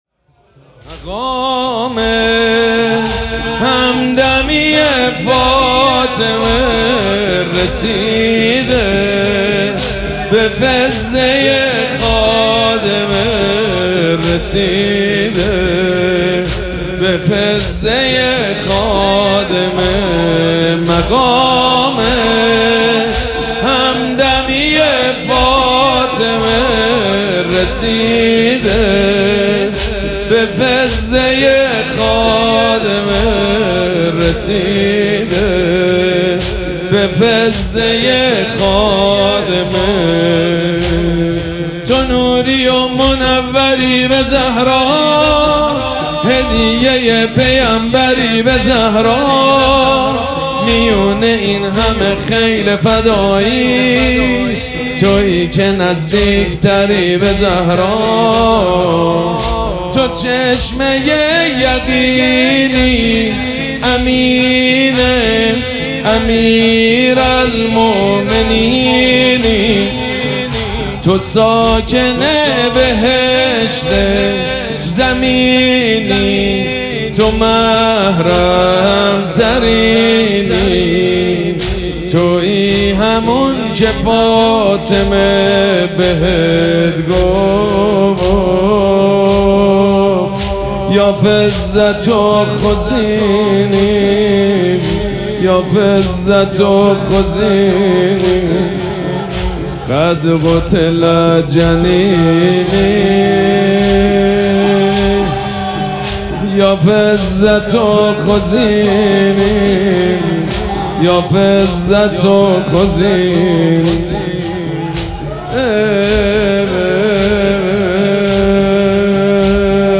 شب اول مراسم عزاداری دهه دوم فاطمیه ۱۴۴۶
حسینیه ریحانه الحسین سلام الله علیها